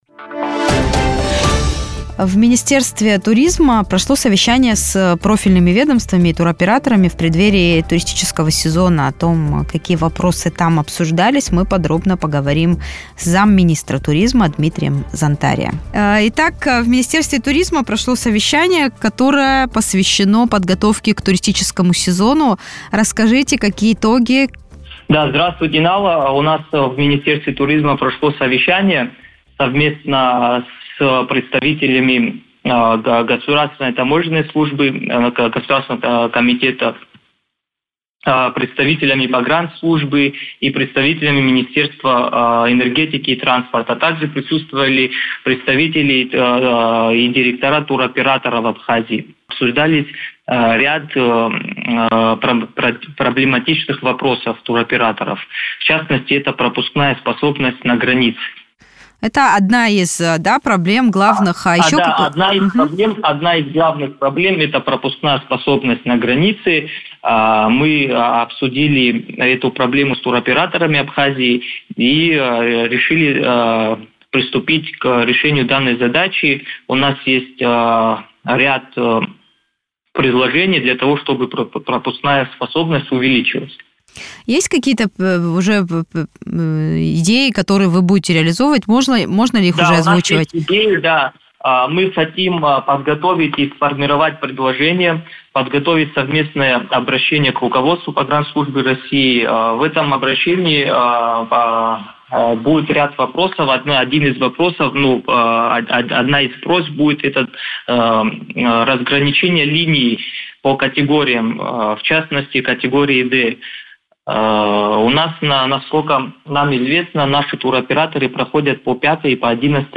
Замминистра туризма Дмитрий Зантария в интервью радио Sputnik рассказал, какие актуальные вопросы обсуждались на встрече.